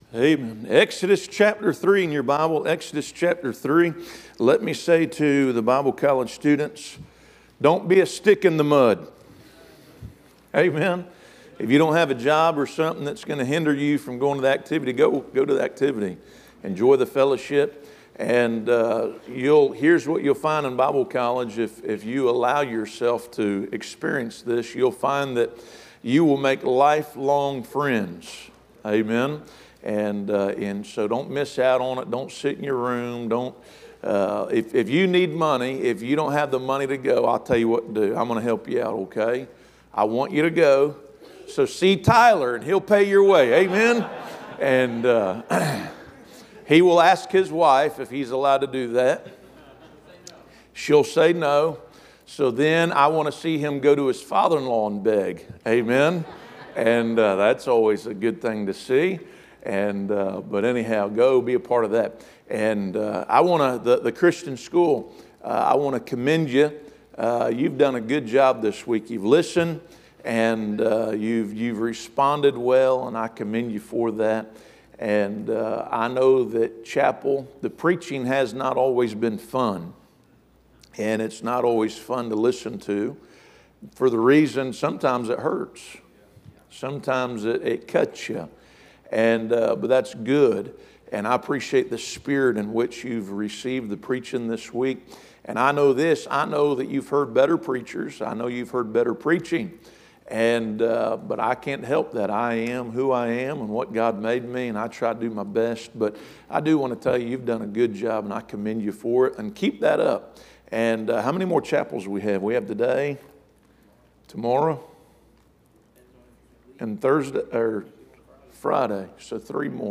Series: Back to School Revival